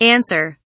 answer kelimesinin anlamı, resimli anlatımı ve sesli okunuşu